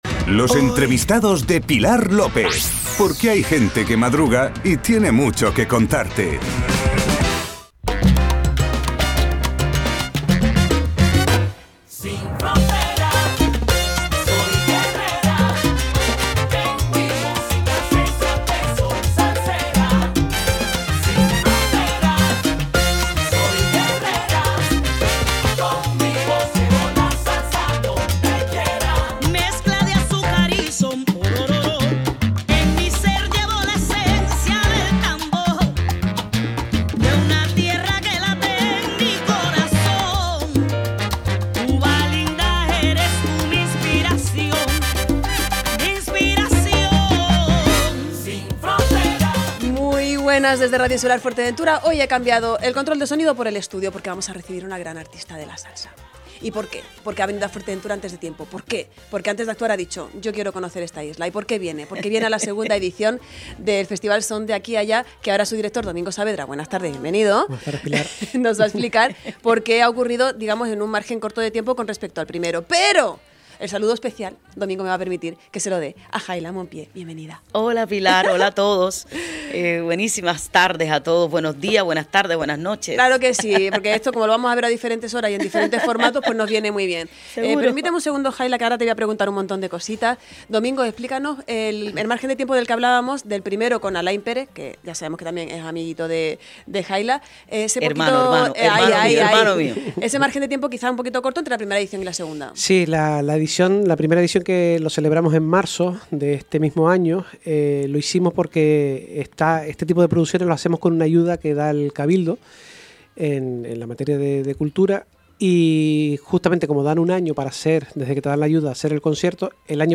Entrevistas
Entrevista-en-Radio-Insular-a-Haila-Mompie-La-Cubanisima.mp3